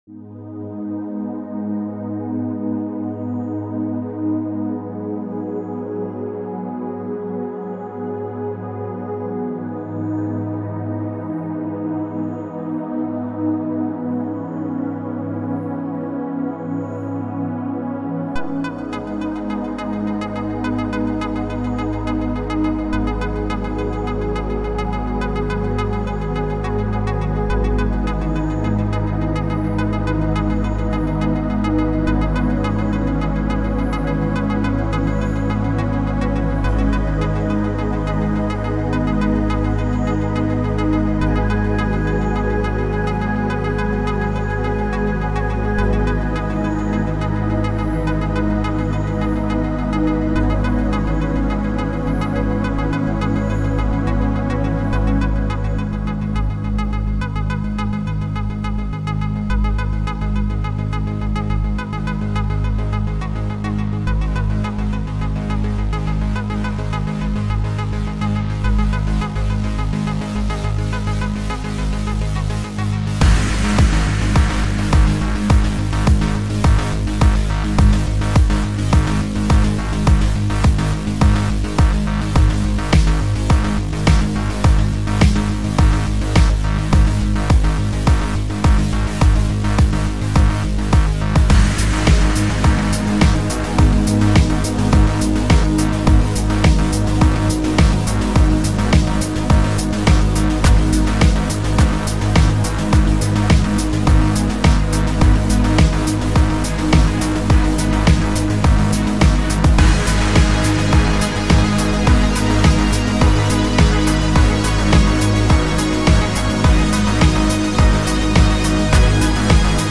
It isn't that bad as instrumental xd